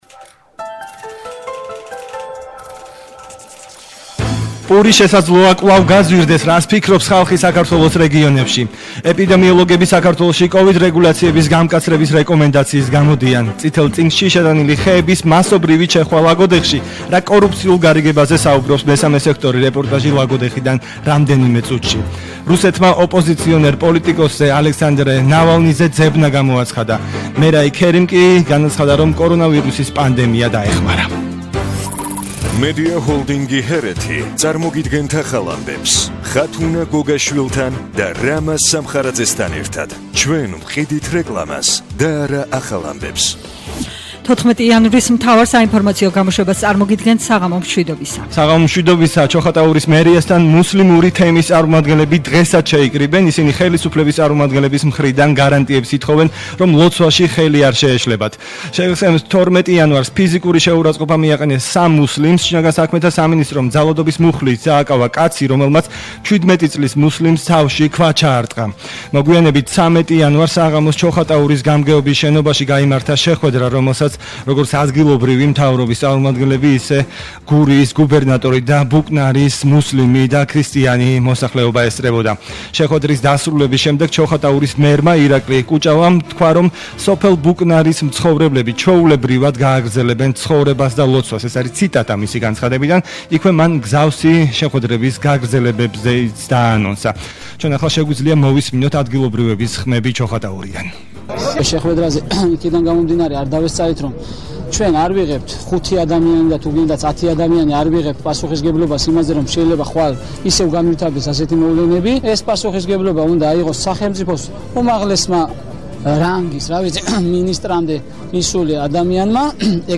მთავარი საინფორმაციო გამოშვება –14/01/21 - HeretiFM